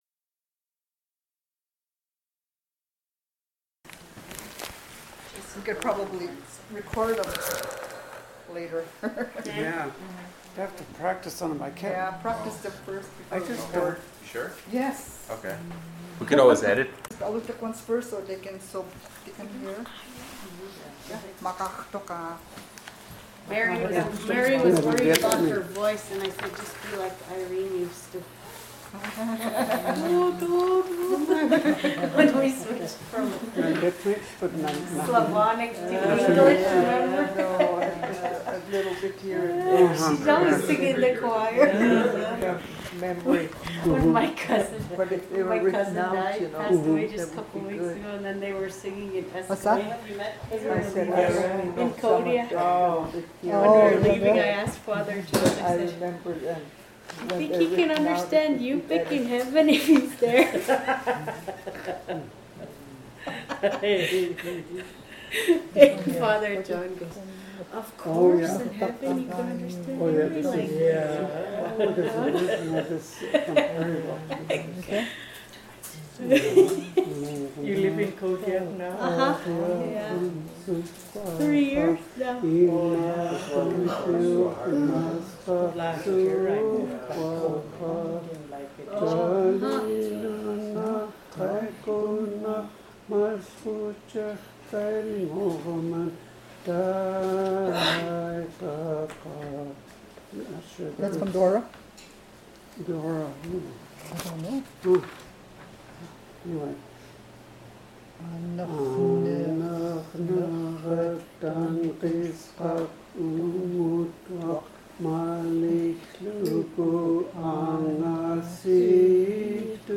Access audio Summary: group of elders singing Alutiiq/russian songs Description: group of elders singing Alutiiq/russian songs Original Format: MiniDisc (AM470:326A) Migration: CD (AM470:326B) Location: Location Description: Kodiak, Alaska